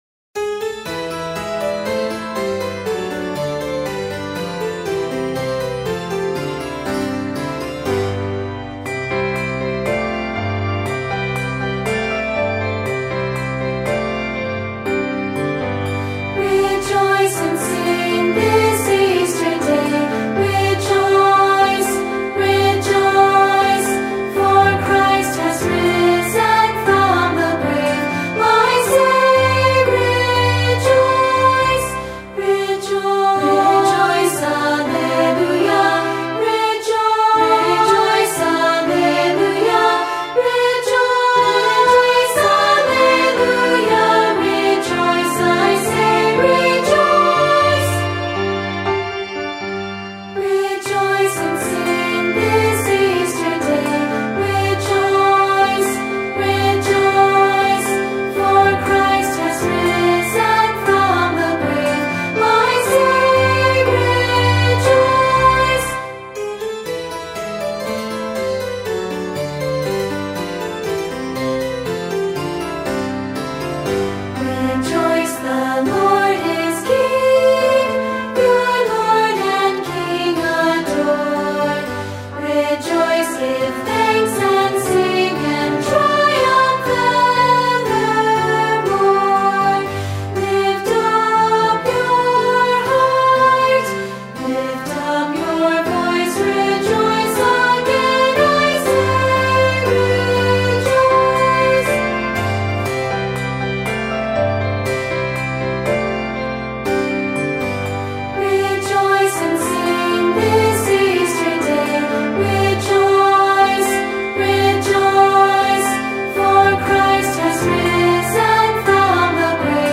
Voicing: Unison|2-Part